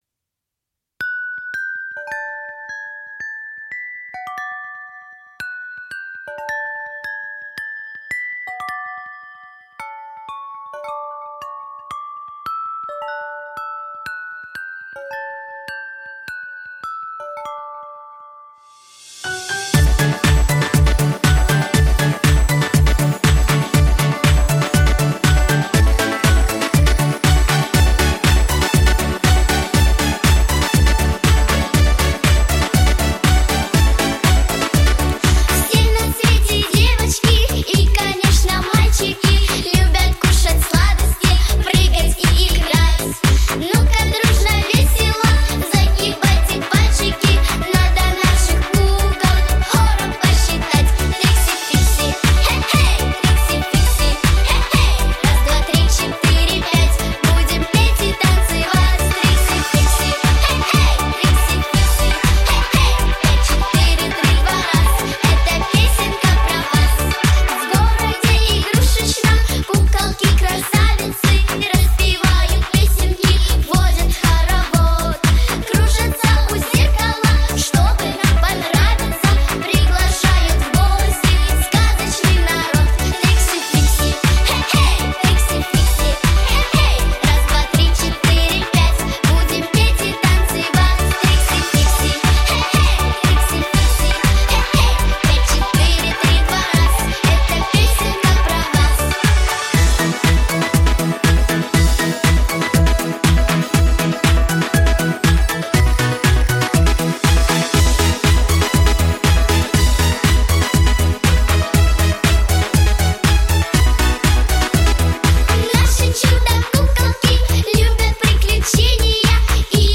• Жанр: Детские песни